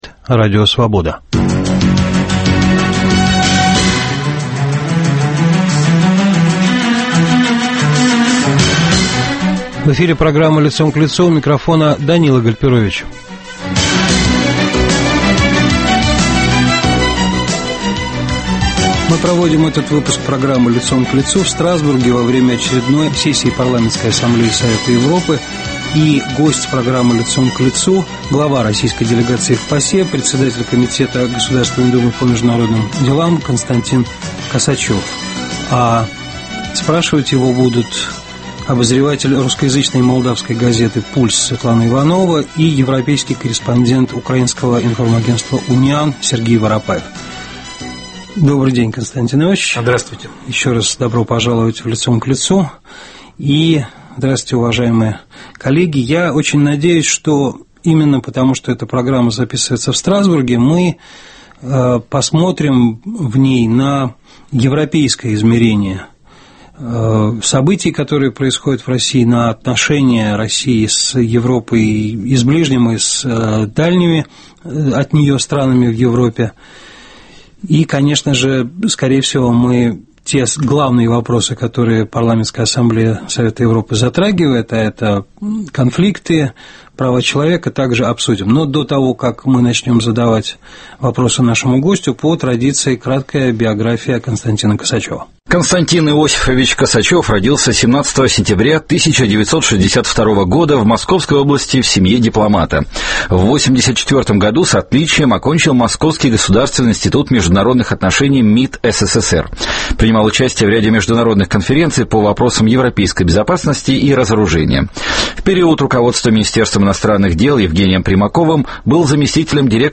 Лидер в той или иной сфере общественной жизни - человек известный и информированный - под перекрестным огнем вопросов трех журналистов: российского, иностранного и ведущего "Свободы".